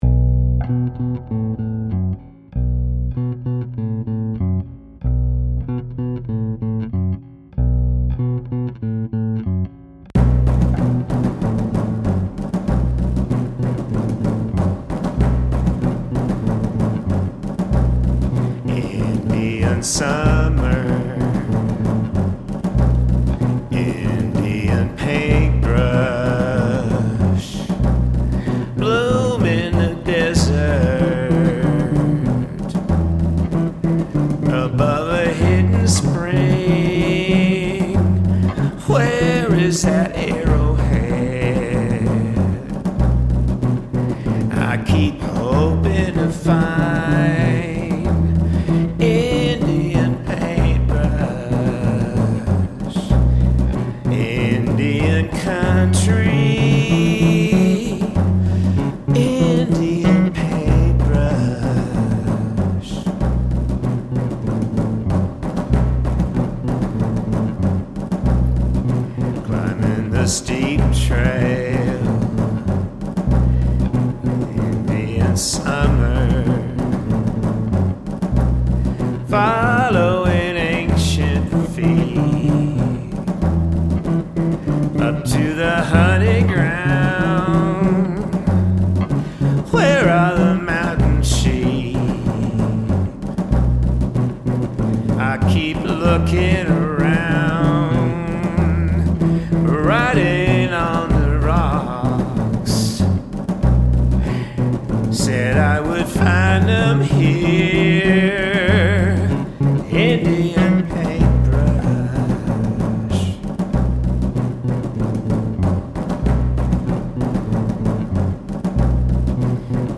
But I wanted to share these lo-fi recordings of works in progress with our community, because who knows if I’ll ever have time to polish them for mass consumption?
I started composing most of them with the electric bass